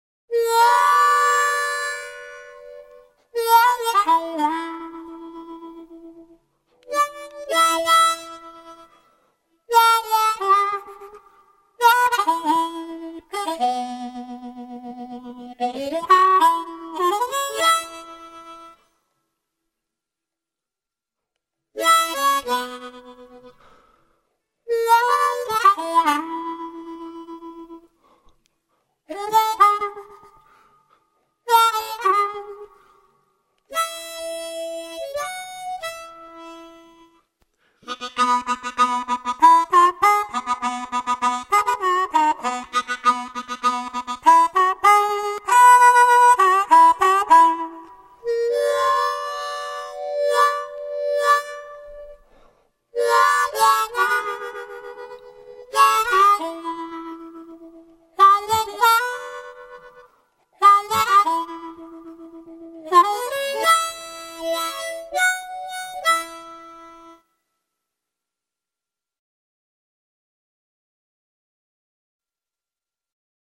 Грустные мелодии на губной гармошке